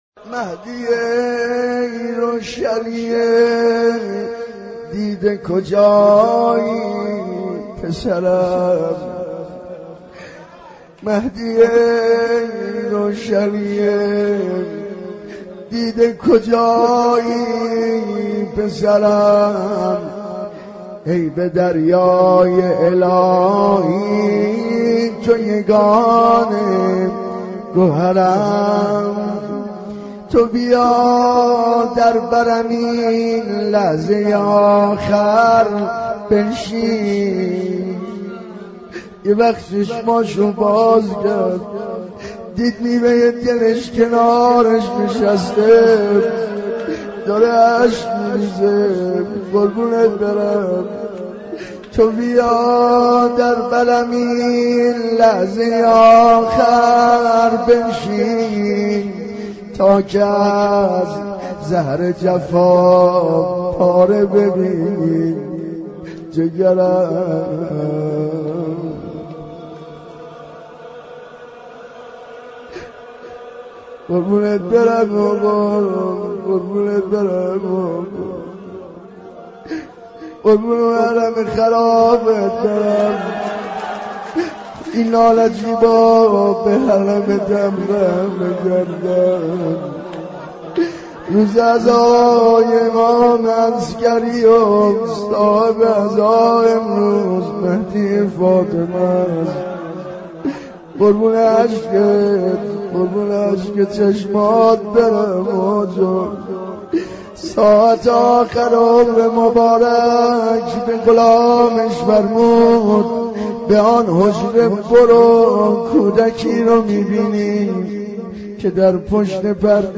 مناجات
مناجات-با-امام-زمان-ع-24.mp3